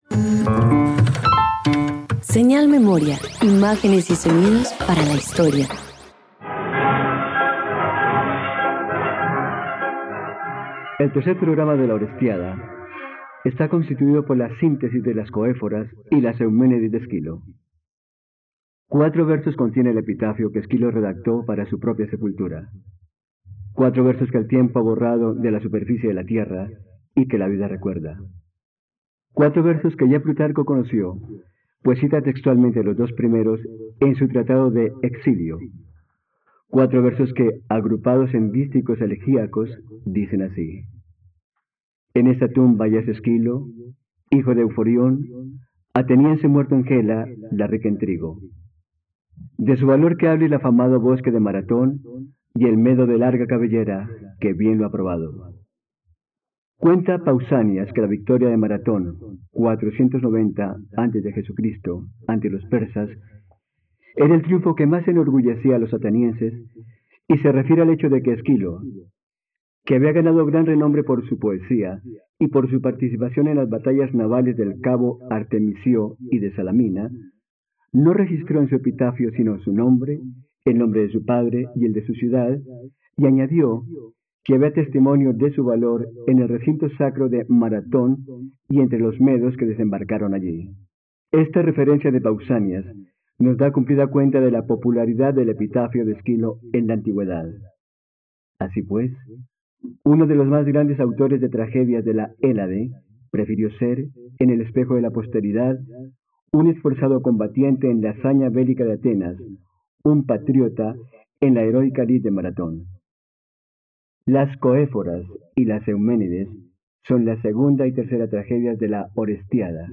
Las Coéforas y Las Euménides - Radioteatro dominical | RTVCPlay
..Radioteatro. Escucha Las Coéforas y Las Euménides, dos obras de Esquilo que hacen parte de la trilogía que lleva el nombre de ‘La Orestiada’, disponible en la plataforma de streaming RTVCPlay.